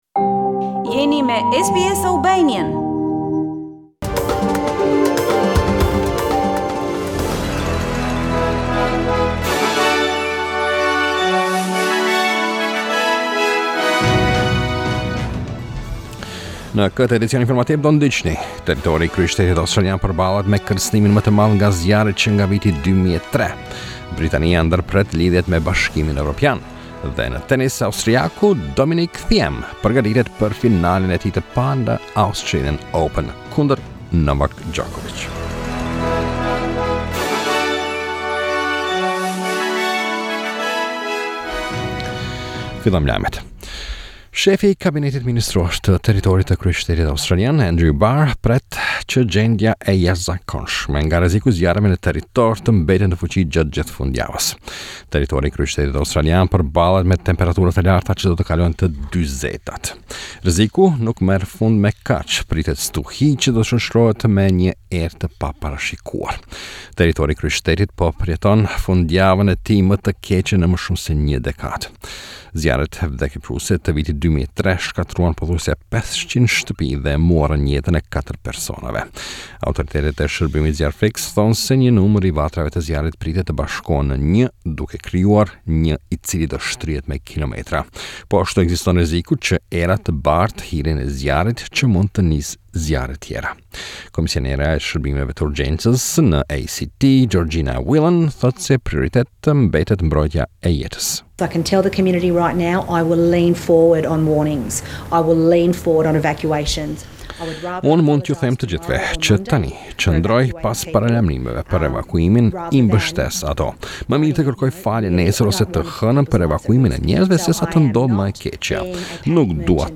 SBS News Bulletin 01 February 2020